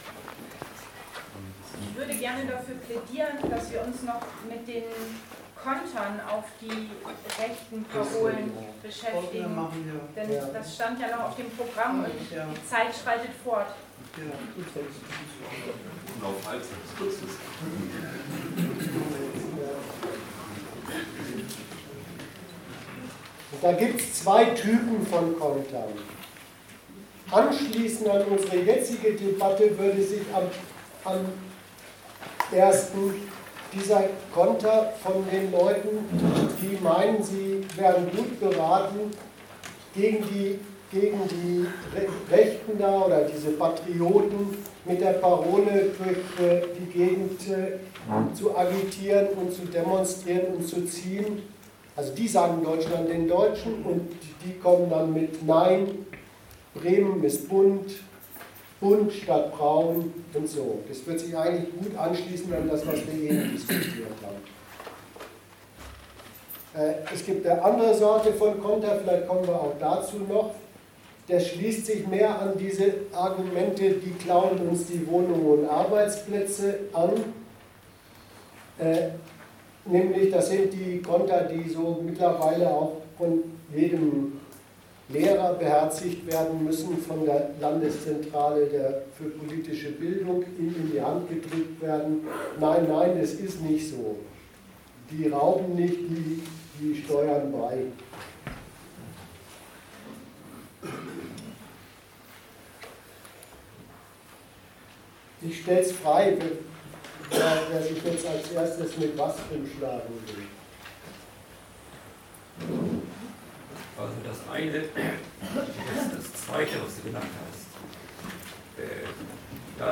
Oder darf man vorher einmal fragen, was die vorgetragenen Parolen überhaupt taugen? Wir sind so frei, dieser Frage in einer Diskussion mit allen Interessierten nachzugehen, gleichgültig, welchem der beiden Lager sie sich zuordnen mögen oder ob sie noch ganz andere, eigene Positionen zu Gehör bringen wollen.